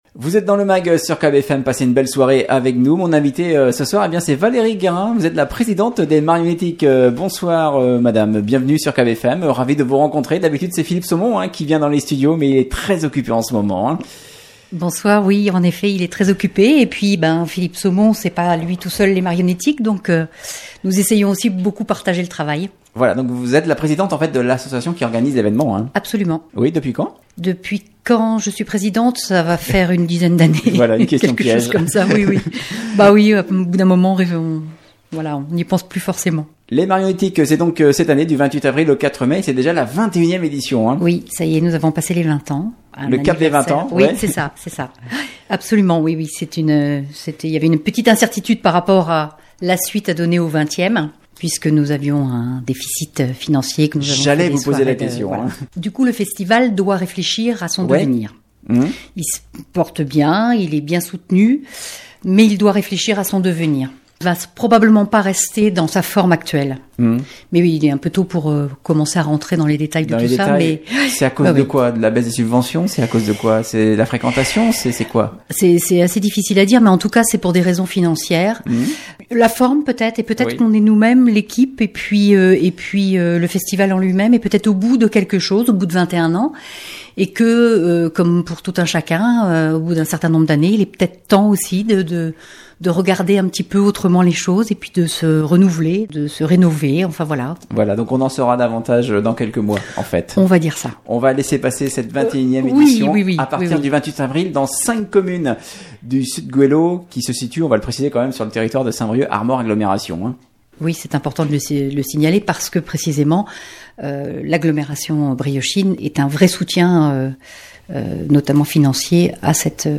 Invitée du Mag hier soir